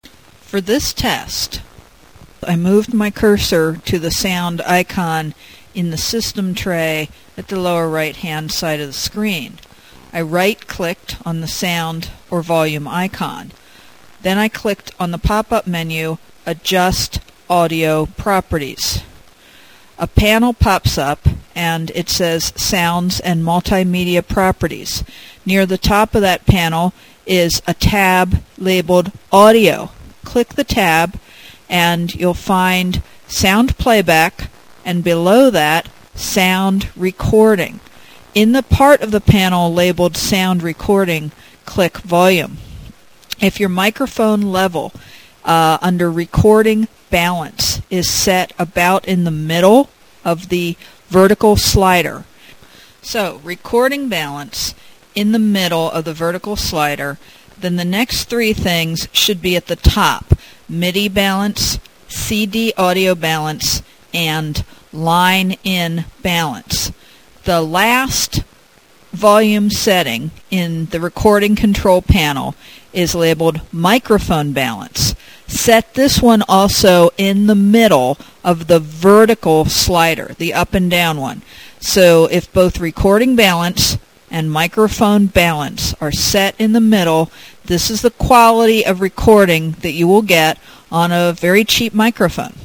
For voice quality equivalent to what you hear on these pages, you can record at 11.025 KHz 8-bit mono.